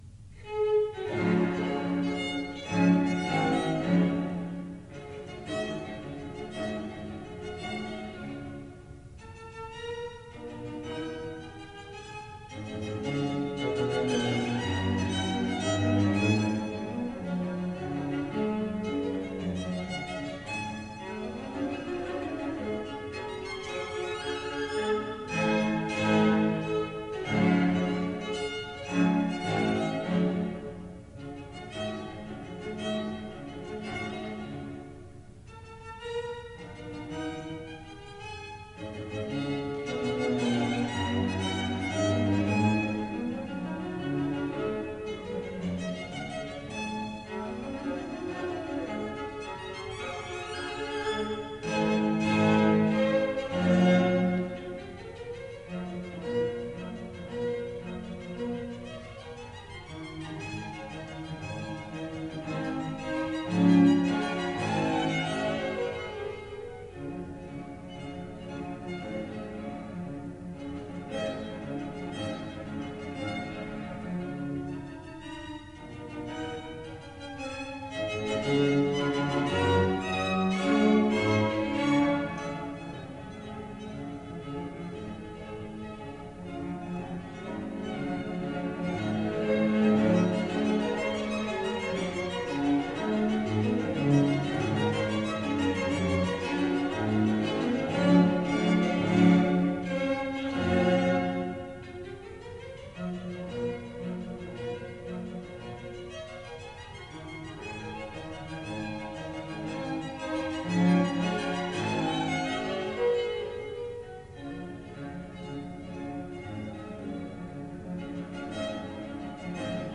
Classical
violin
viola
cello